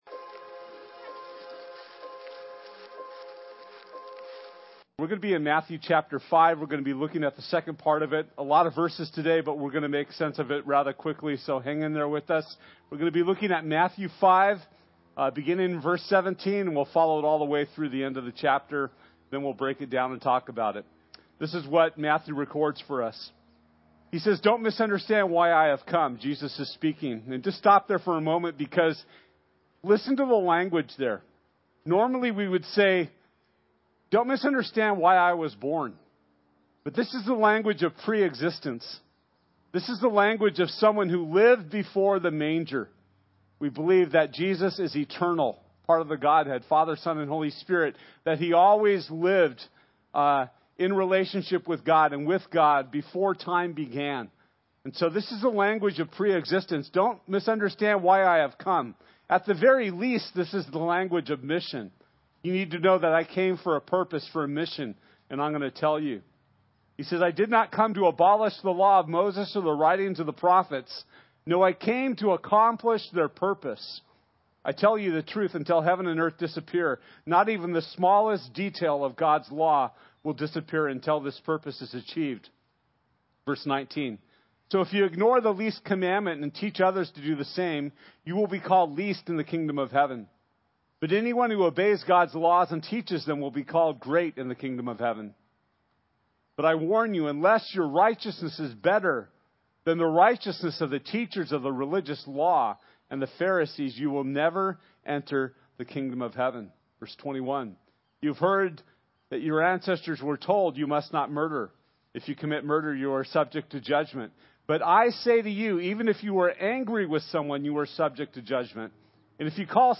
Matthew 5:7-48 Service Type: Sunday This week we’ll be studying the second part of Matthew 5